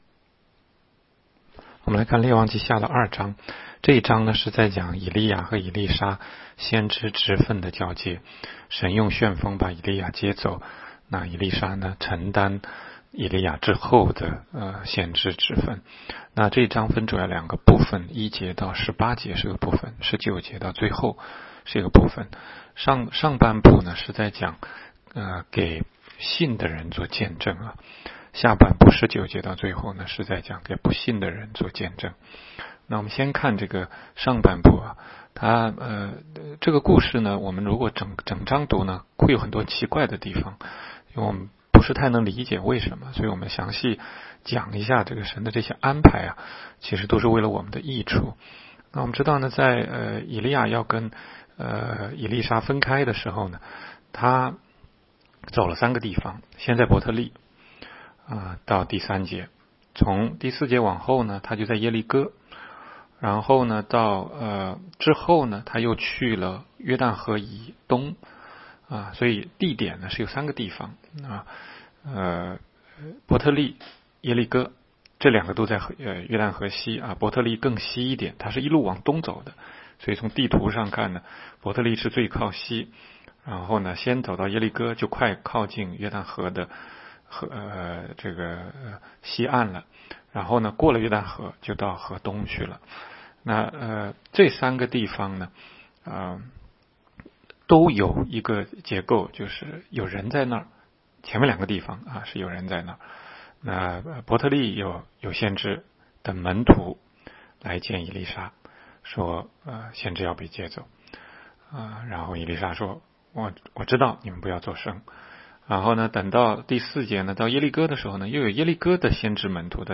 每日读经
每日读经-王下2章.mp3